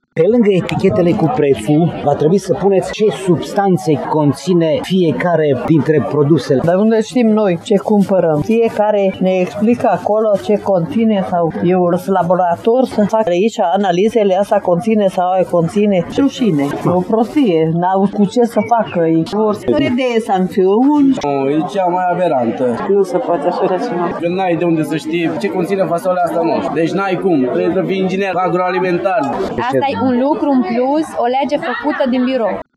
Este o prevedere abuzivă, ce nu poate fi îndeplinită, susțin vânzătorii din P-ța Cuza Vodă din Tg.Mureș, pentru că ei nu dețin informațiile tehnice și nici nu au cum să le obțină: